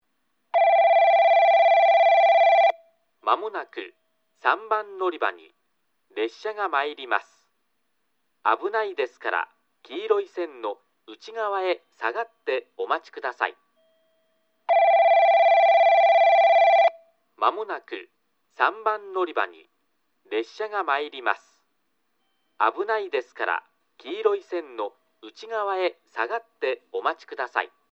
放送は上下とも1，3番のりばが男声、2，4番のりばが女声で固定されています。スピーカーは旧放送同様TOAラッパ型から流れ、クリアホーンからは遠隔放送が流れます。
3番のりば接近放送　男声